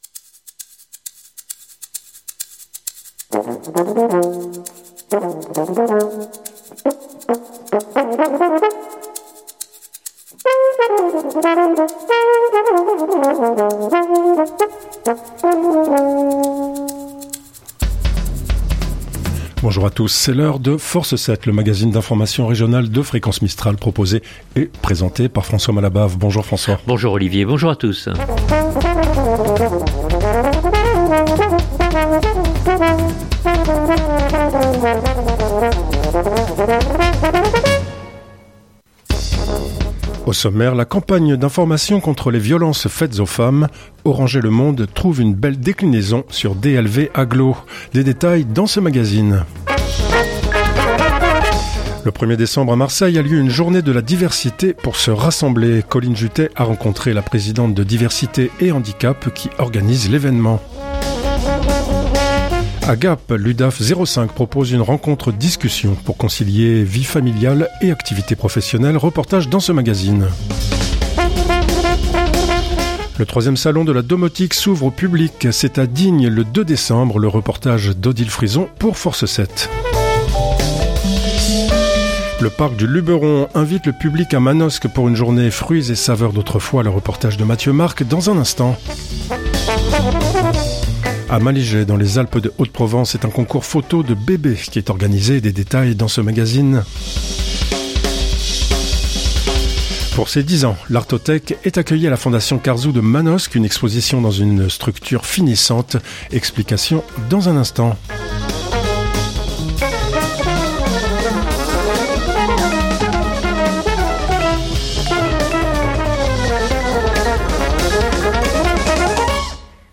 Reportage dans ce magazine.